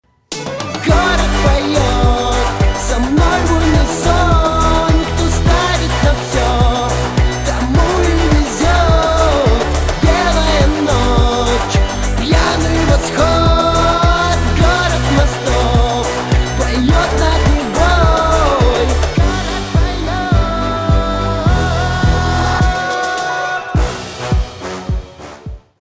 скрипка
барабаны , битовые , басы , качающие